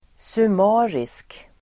Uttal: [sum'a:risk]